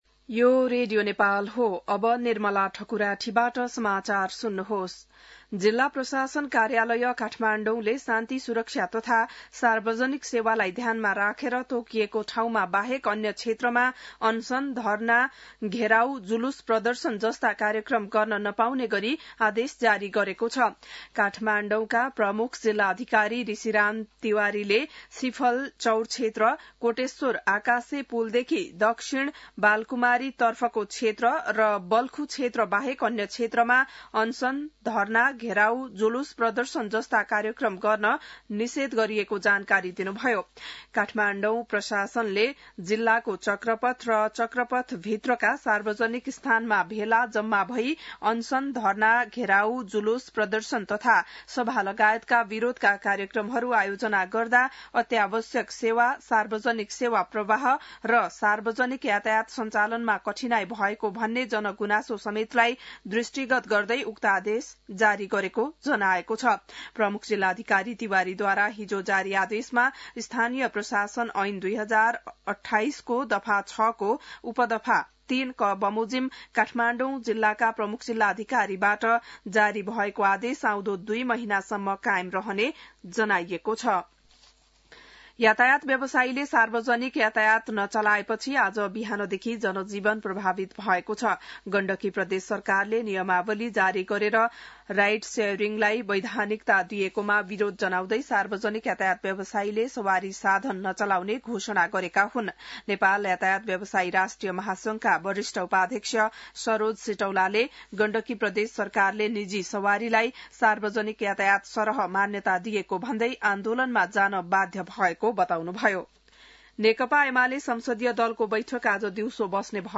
बिहान १० बजेको नेपाली समाचार : १९ जेठ , २०८२